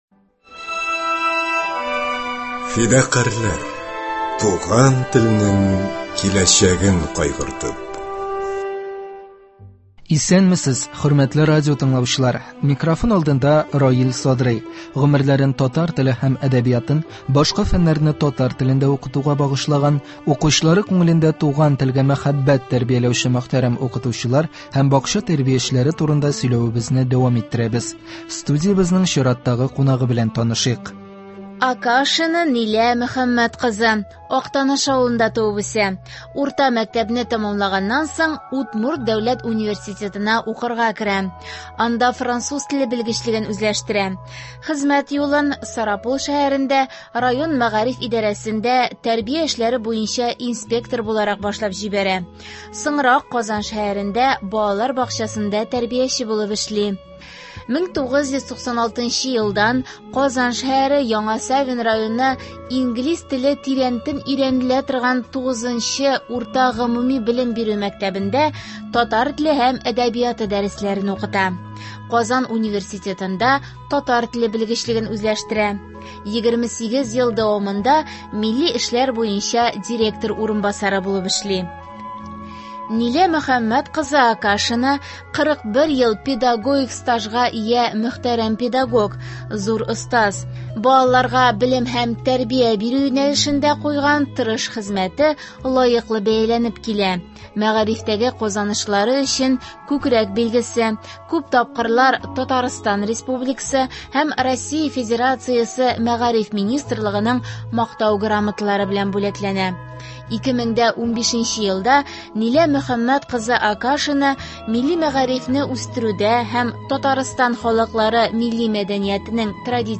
Студиябезнең чираттагы кунагы